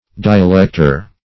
Dialector \Di`a*lec"tor\, n. One skilled in dialectics.